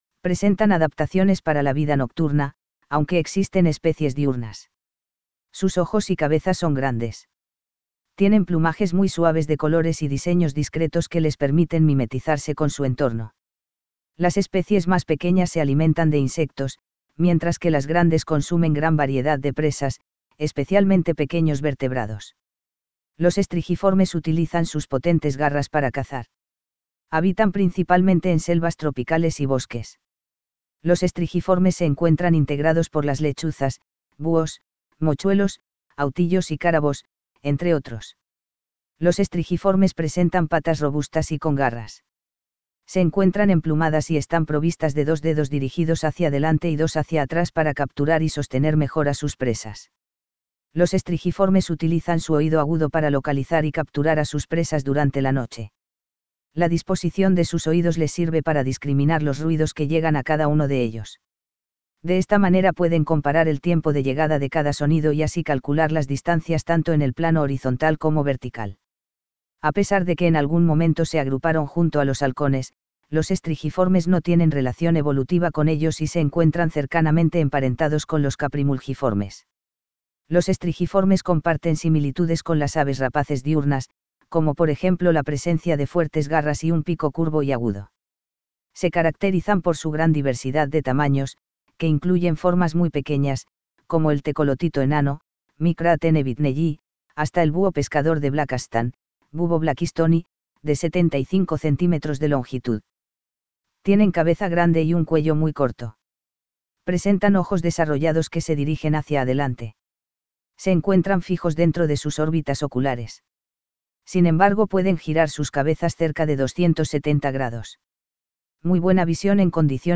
STRIGIFORMES
Tyto alba tuidara - Lechuza de campanario
Bubo virginianus - Ñacurutú
Megascops (Otus) choliba - Tamborcito común
La mayoría de las especies de ambos grupos pueden emitir fuertes y variadas vocalizaciones, las cuales son utilizadas para atraer o ahuyentar a sus congéneres.